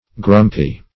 grumpy - definition of grumpy - synonyms, pronunciation, spelling from Free Dictionary
Grumpy \Grump"y\, a. [Cf. Grumble, and Grum.]